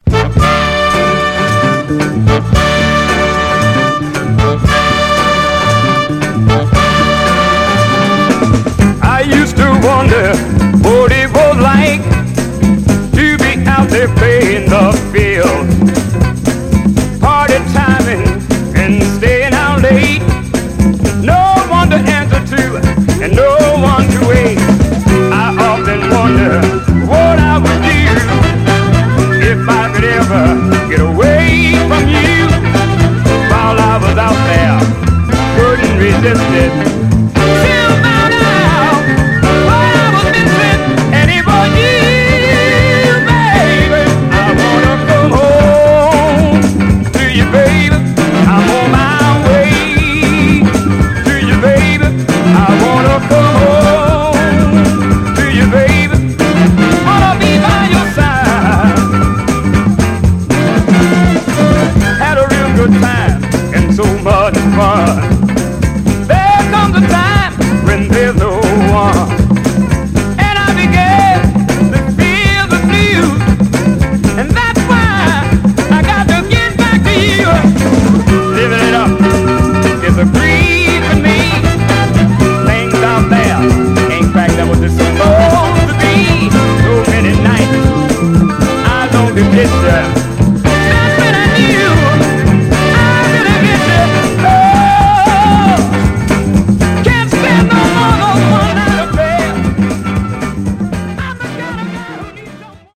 高揚感のあるホーン隊に込み上げヴォーカルも堪らない、ファンキー・ソウル・ダンサー人気曲です！
※試聴音源は実際にお送りする商品から録音したものです※